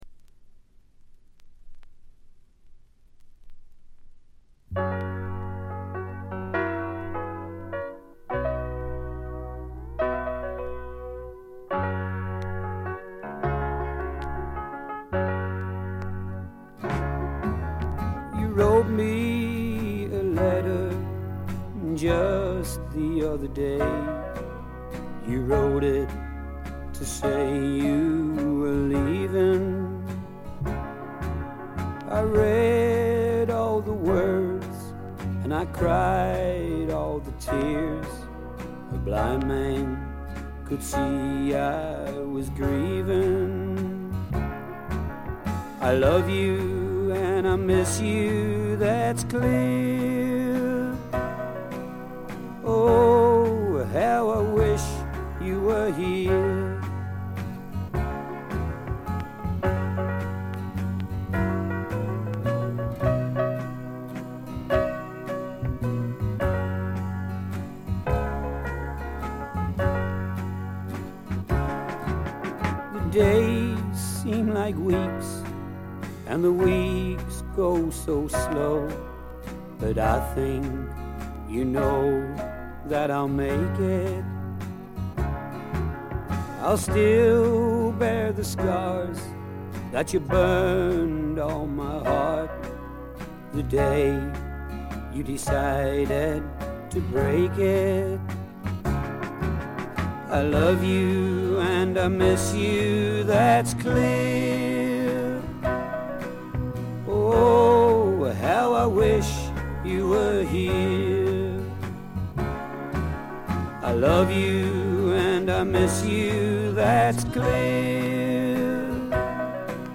B4終わりからB5冒頭にかけて軽い周回ノイズ。
英国のフォーキーなシンガー・ソングライター
弾き語りに近いような控えめでセンスの良いバックがつく曲が多く、優しい歌声によくマッチしています。
試聴曲は現品からの取り込み音源です。